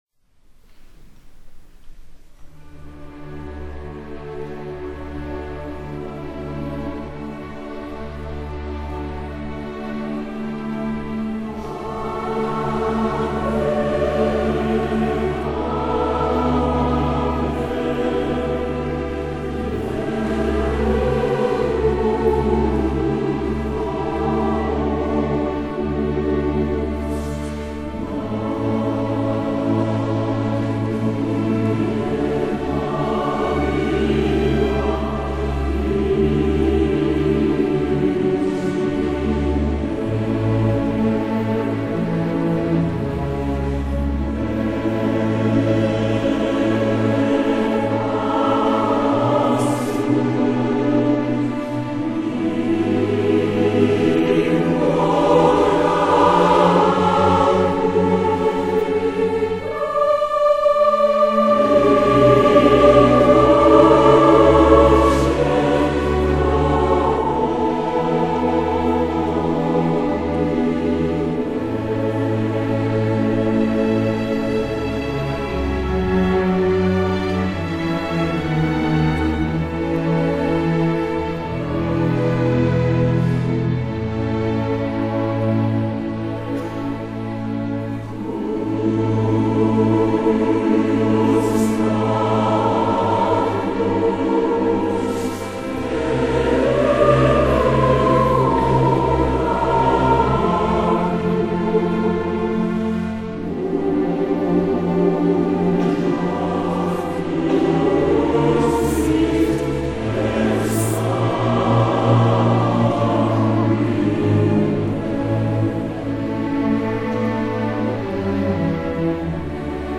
１．東芝フィルハーモニー合唱団 　　　（曲名をクリックしてください。）
ミューザ川崎シンフォニーホール
BGM→　アンコール曲：　モーツアルト作曲　　　　　　　　　　　　　「Ave Verm Corpus」　　　　　3:55分